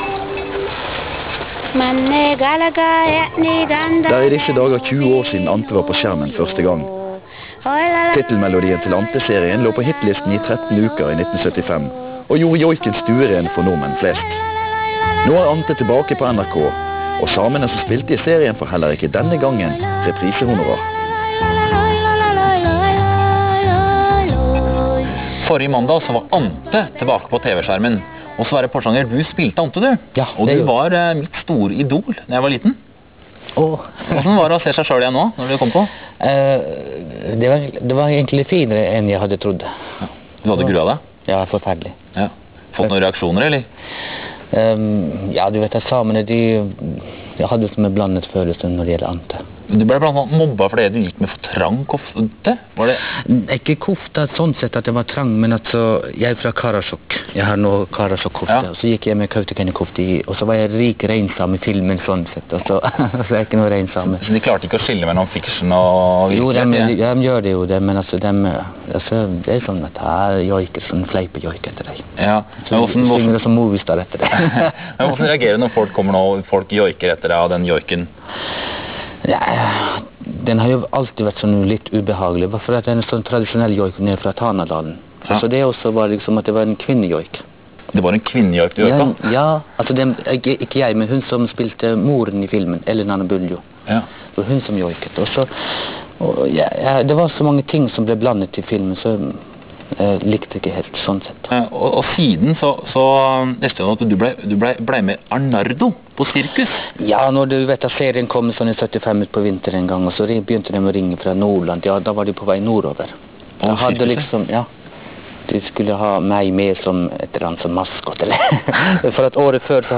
Lille Lørdag fjernsynsradio: Intervju